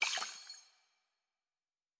pgs/Assets/Audio/Fantasy Interface Sounds/Potion and Alchemy 09.wav at master
Potion and Alchemy 09.wav